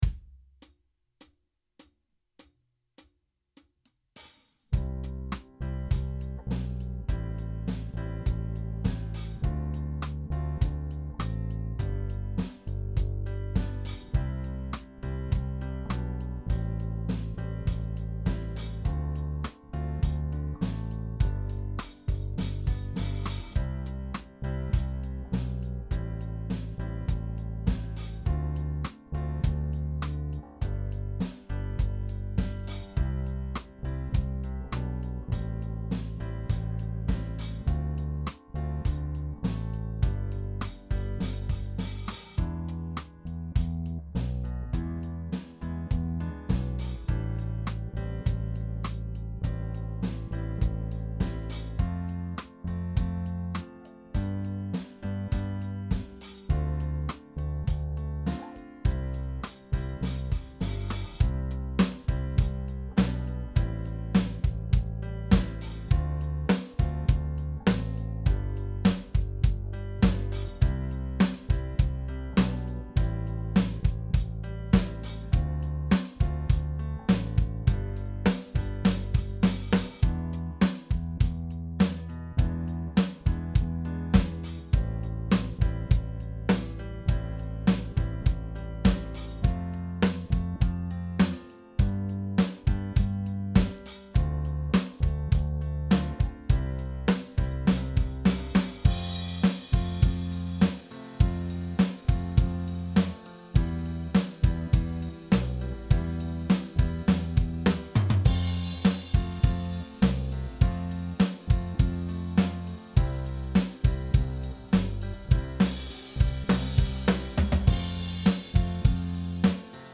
Cover version
Soul, jazzy style.